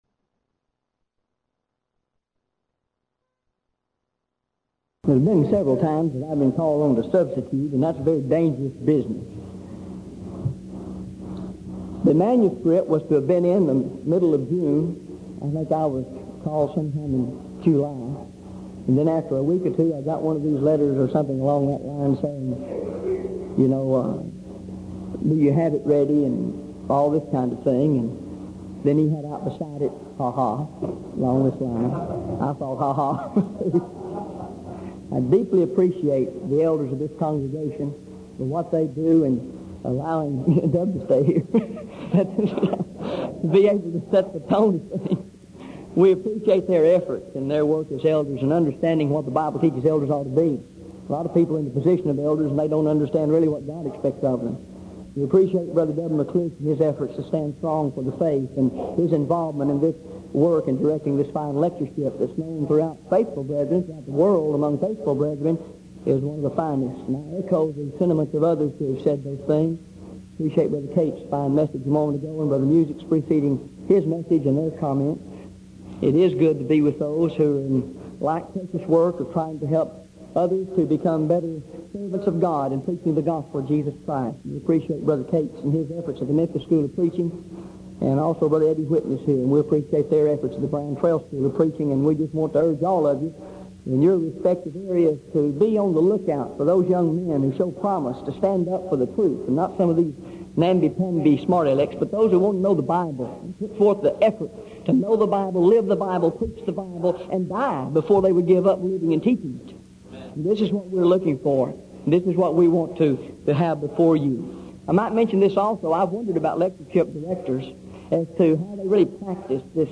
Event: 1986 Denton Lectures Theme/Title: Studies in Galatians